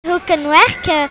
prononciation Hoekenwerk ↘ explication Il y a un jeu qui s’appelle 'hoekenwerk’.
hoekenwerk_p.mp3